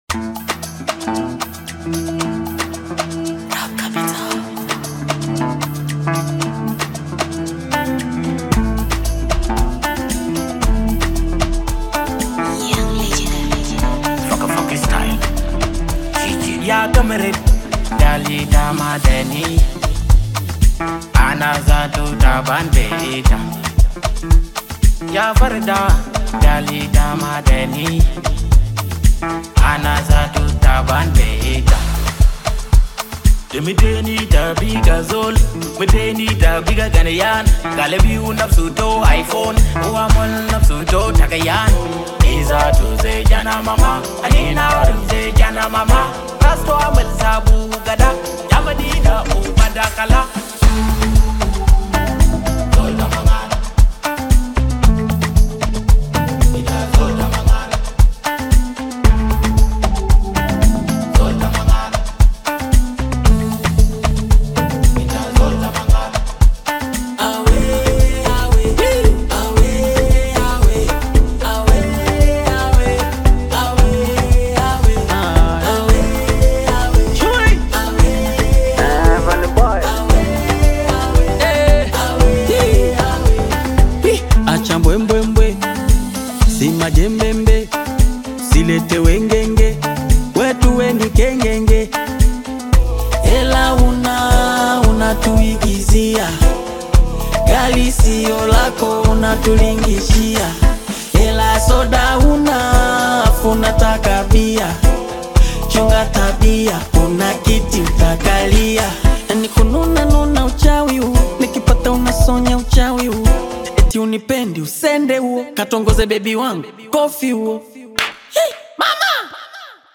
Bongo Flava
Bongo Flava song